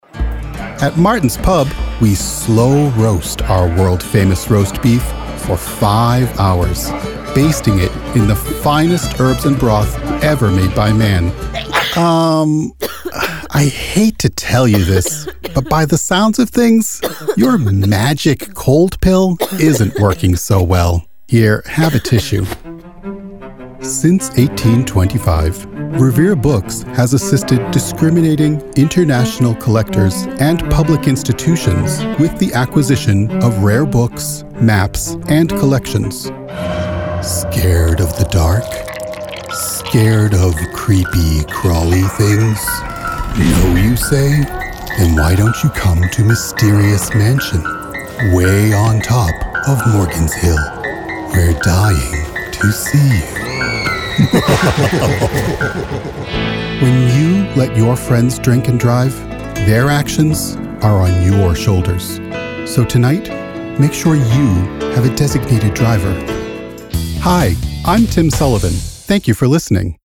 An intelligent, neighborly voice.
Commercial Demo
General American, Boston, New England